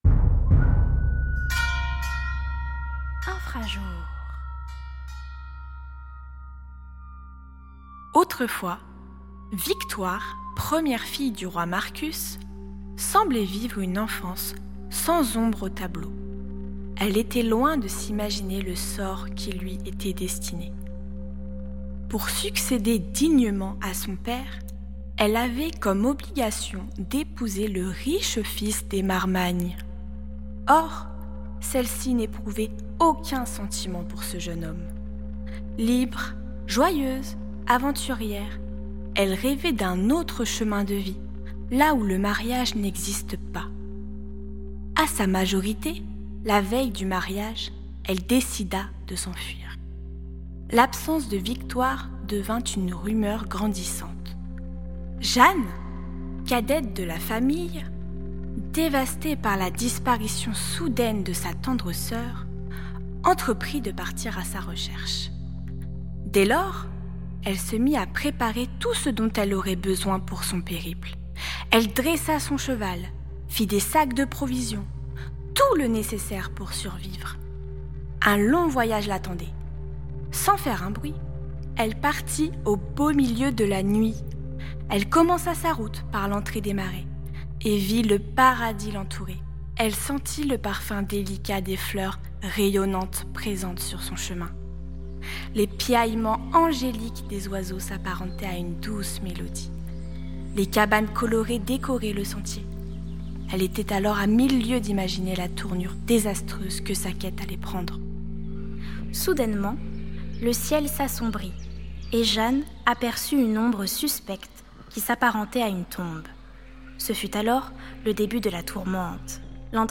Musiques et effets sonores